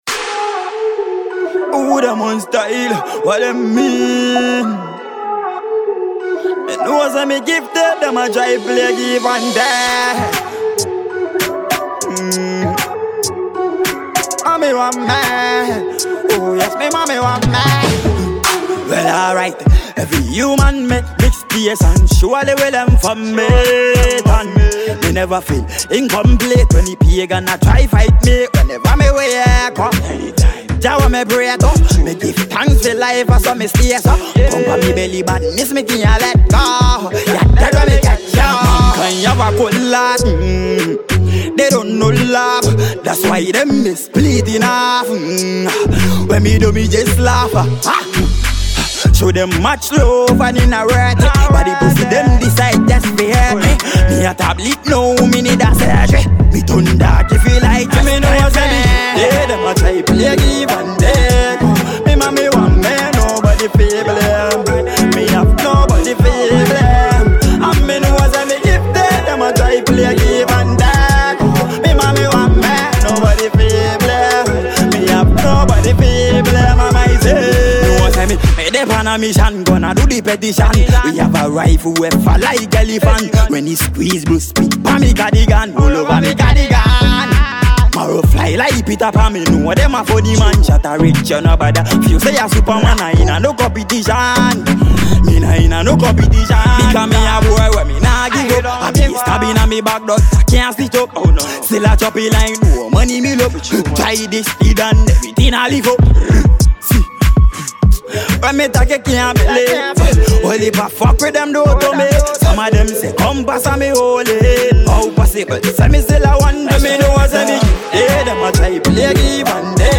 a Ghanaian dancehall act